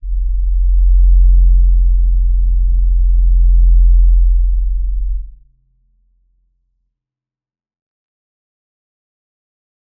G_Crystal-F1-mf.wav